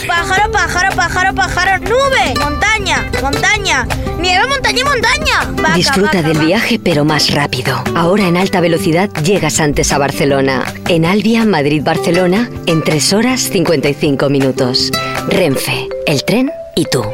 Publicitat del tren d'alta velocitat Alvia